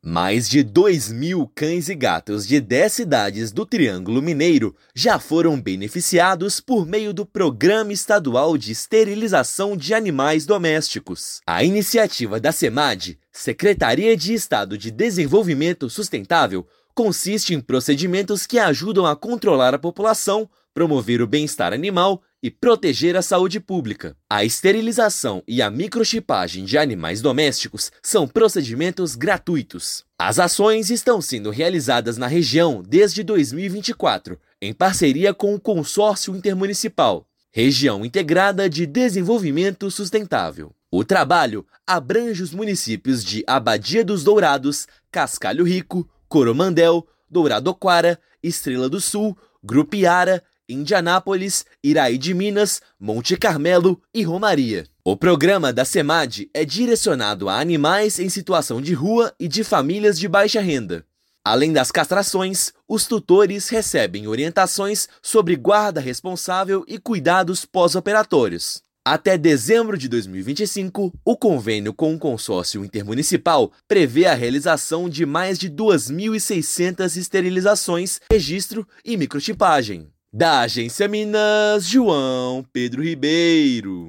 [RÁDIO] Mais de 2 mil cães e gatos já foram castrados em dez municípios do Triângulo Mineiro desde 2024
Convênio da Semad com consórcio intermunicipal prevê mais de 2,6 mil esterilizações até o fim do ano, além de registro e microchipagem de animais domésticos. Ouça matéria de rádio.